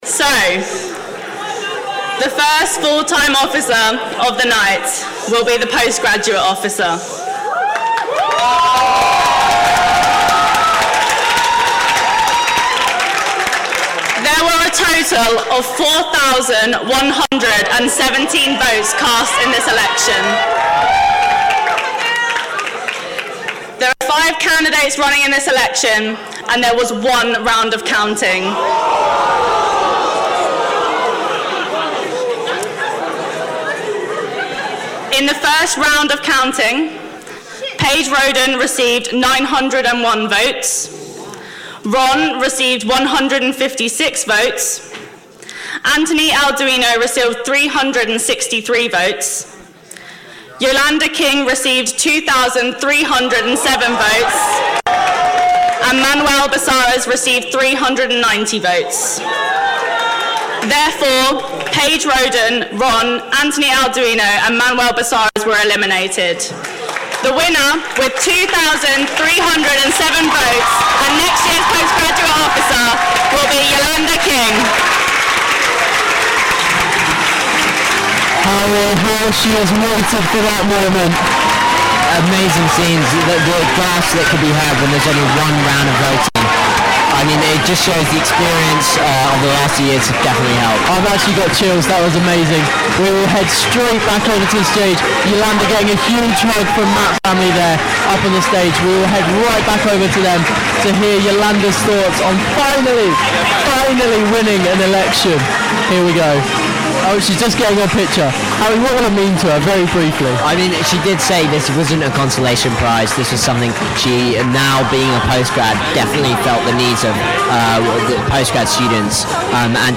SU Elections - Post Grad announcement and interview